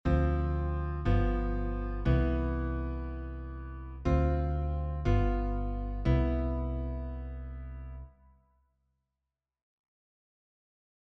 ガイド・トーン・ボイシングは、コードの3rdと7th（ガイド・トーン）の2音で構成されるシンプルなボイシングです。
Ⅱm7-Ⅴ7-ⅠΔケーデンス（Key C)の例はこちら。
ガイドトーン・ボイシング（Dm7-G7-CΔ)ベースあり